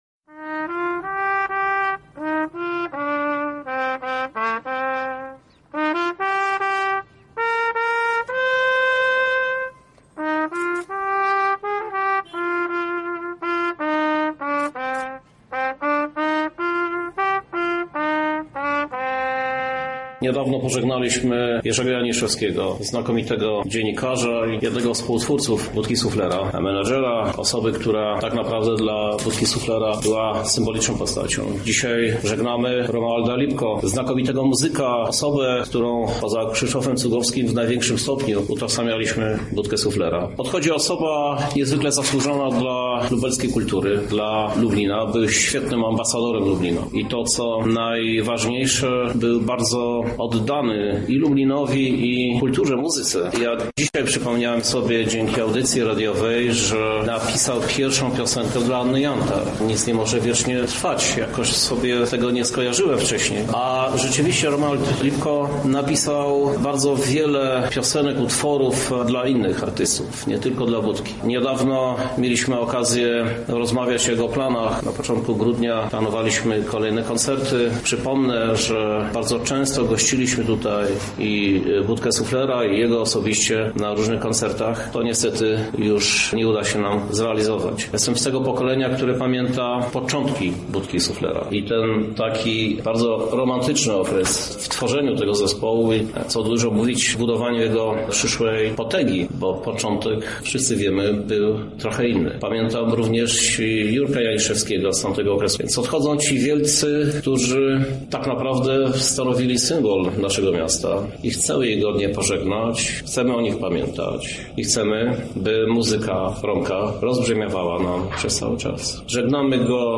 To smutny dzień i ogromna strata dla naszego miasta – mówi Krzysztof Żuk, prezydent Lublina